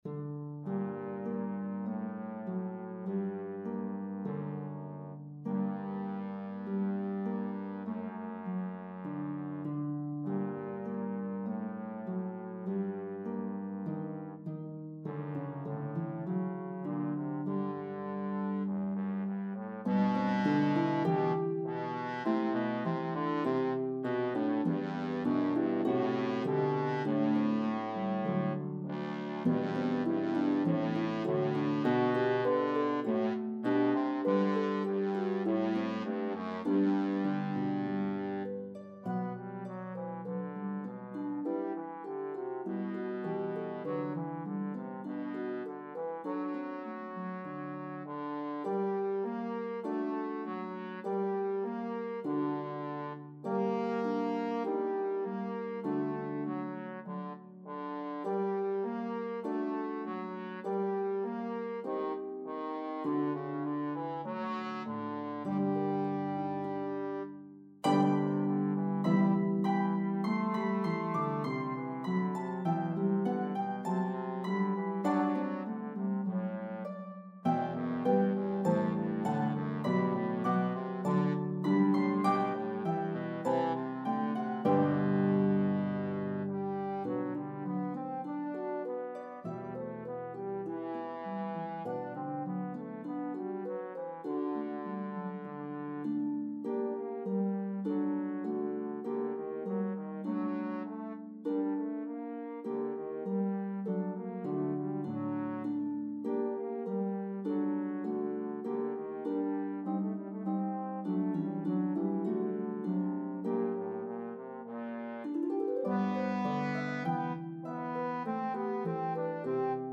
This cherished Carol recounts the birth of Christ.